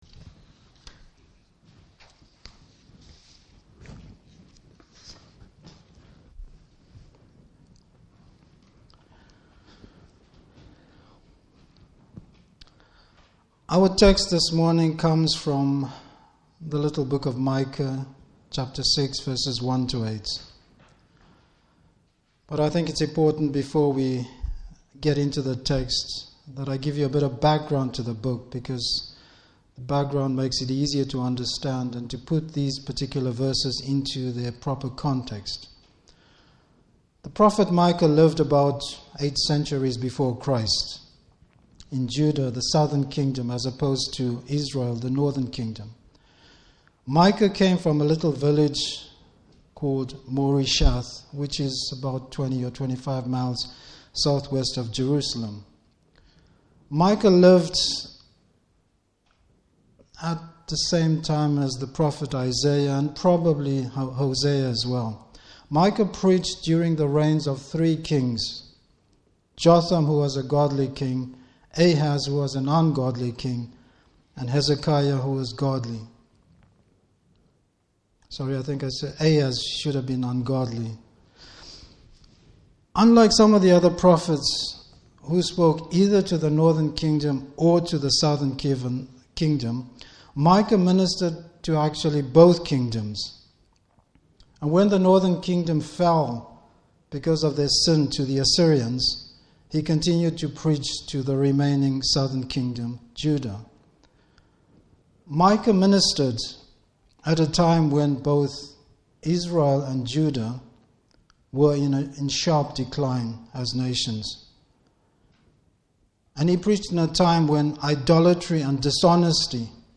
Service Type: Morning Service A Christians lifestyle.